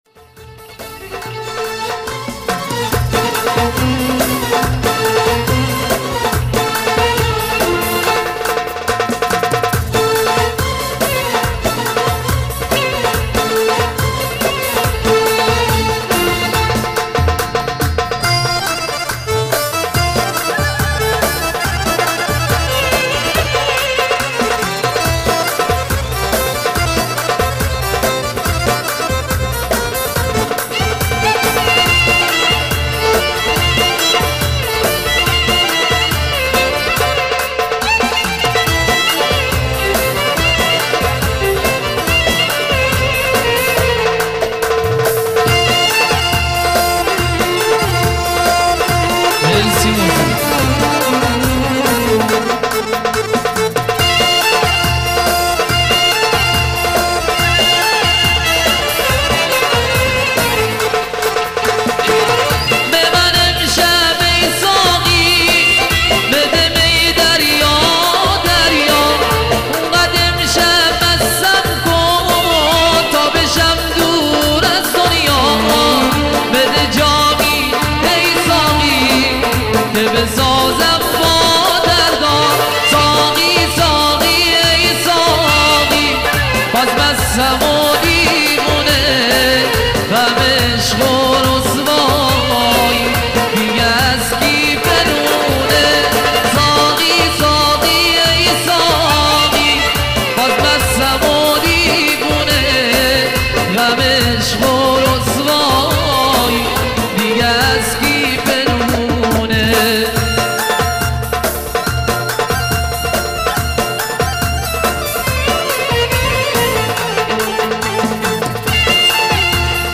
قدیمی غمگین عاشقانه آهنگ (14)
نوع آهنگ : آهنگ قدیمی بازخوانی بسیار زیبا